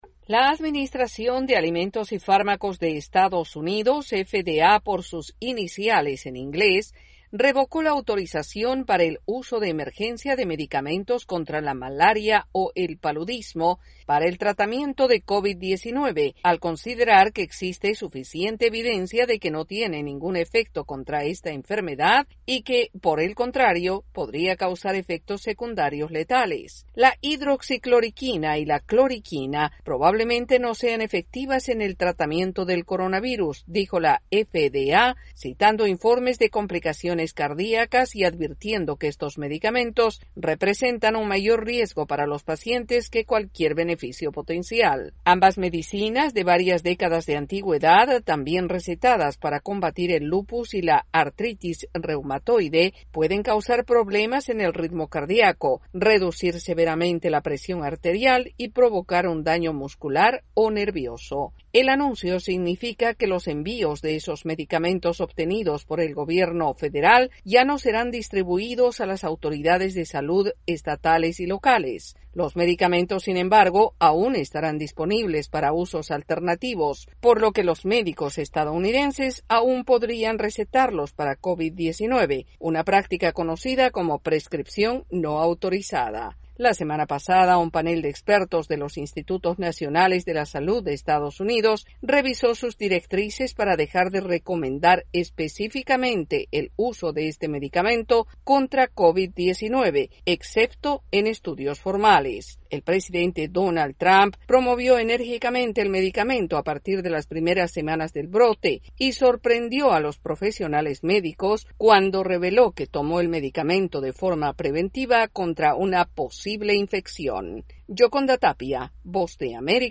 La Administración de Alimentos y Medicamentos de EE.UU. revoca la autorización para el uso de la hidroxicloriquina en tratamientos por el COVID-19. Los detalles en el informe